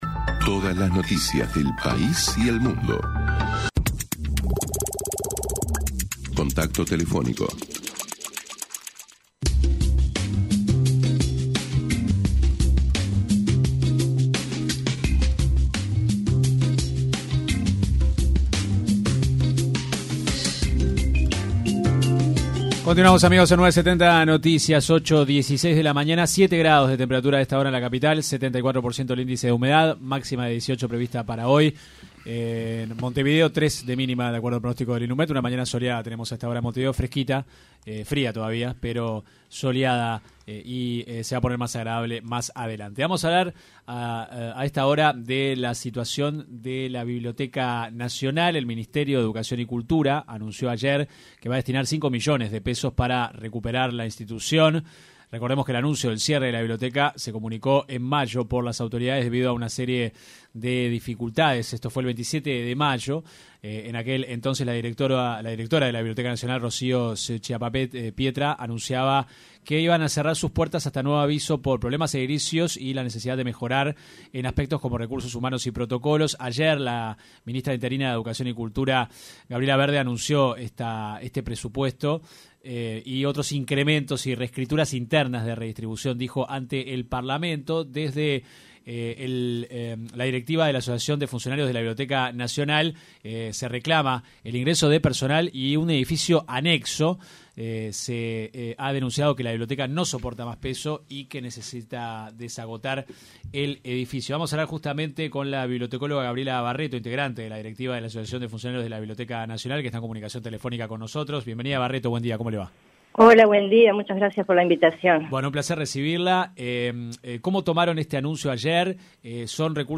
se refirió en diálogo con 970 Noticias, a la situación actual de la institución, reclamó más personal para poder cumplir las tareas, y afirmó que «no vemos posible» reabrir a fin de año, tal como se anunció por parte del Gobierno.